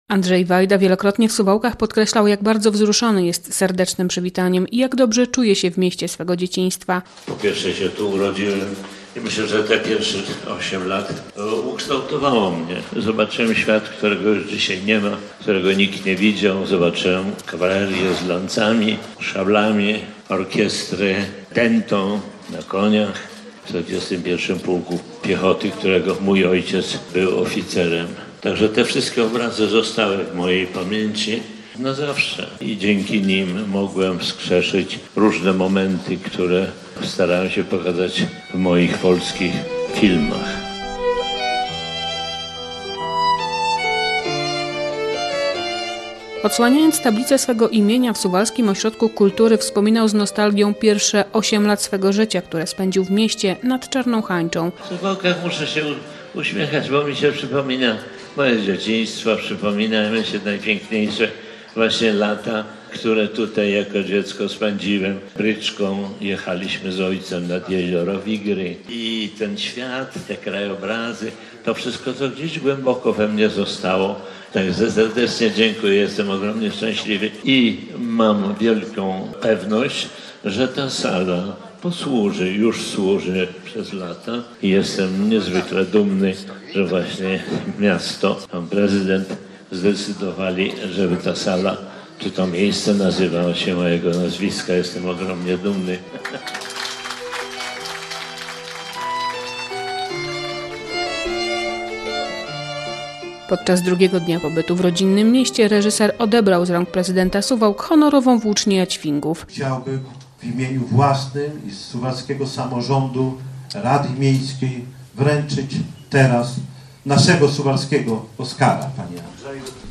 Suwalczanie uczcili 90. rocznicę urodzin Honorowego Obywatela Miasta - Andrzeja Wajdy - relacja